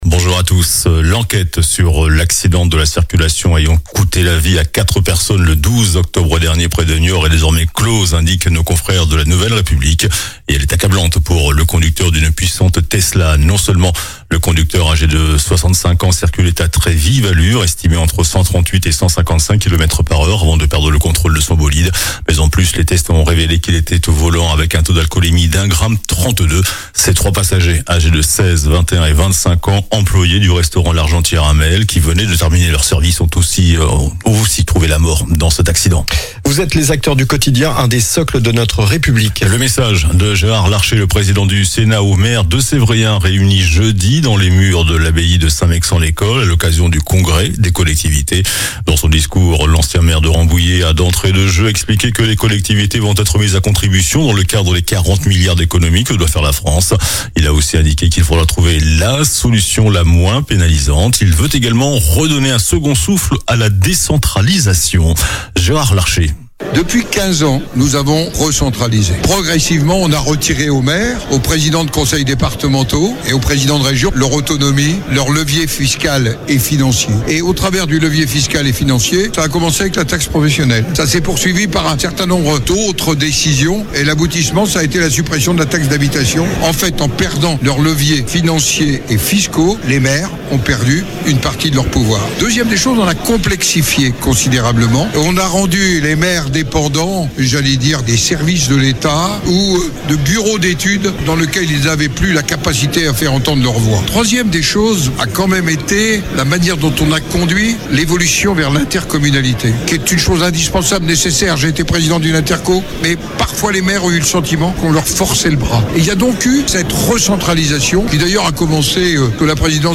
JOURNAL DU SAMEDI 21 JUIN